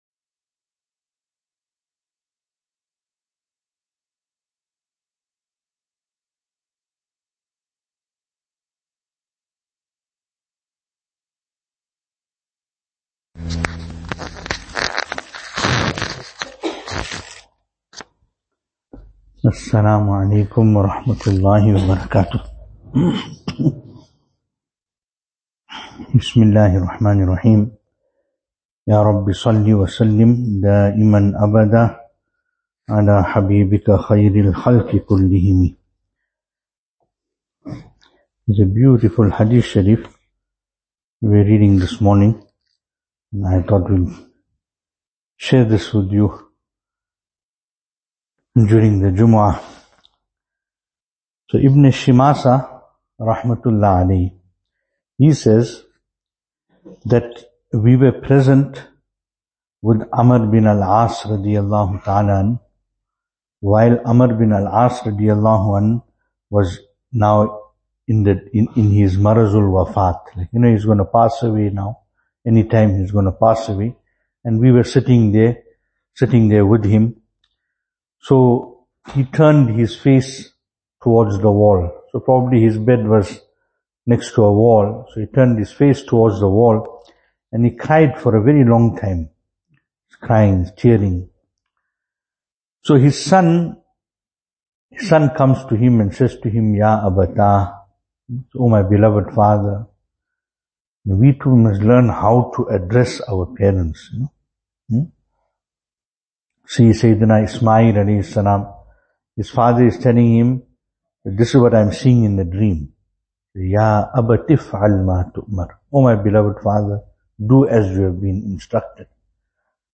2024-06-07 The final moments of the life of Abdullah bin Amr bin Al Aas RadiAllahu anhu Venue: Albert Falls , Madressa Isha'atul Haq Service Type: Jumu'ah